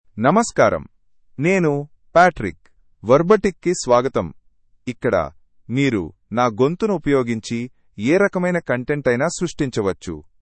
Patrick — Male Telugu AI voice
Patrick is a male AI voice for Telugu (India).
Voice sample
Listen to Patrick's male Telugu voice.
Patrick delivers clear pronunciation with authentic India Telugu intonation, making your content sound professionally produced.